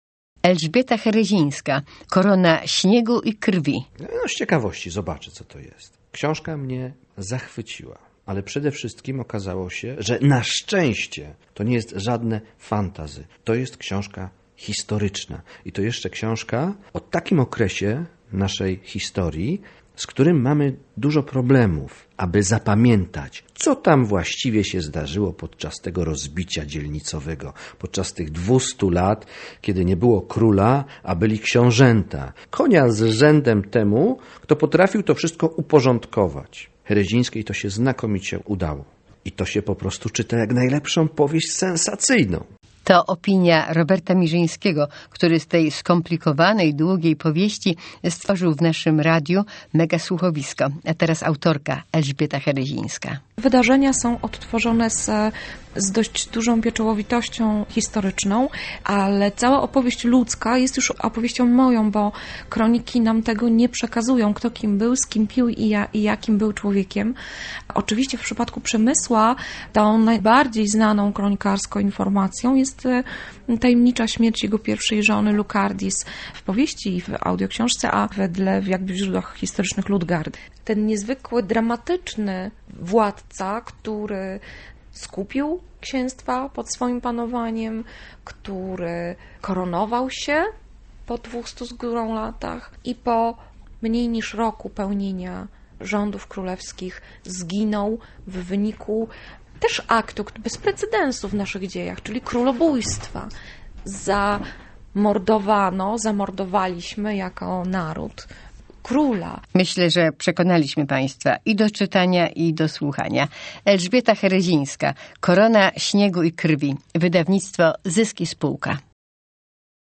Korona śniegu i krwi - nasz audiobook!
Superprodukcja Radia Merkury - audioksiążka "Korona śniegu i krwi" Elżbiety Cherezińskiej - już w księgarniach.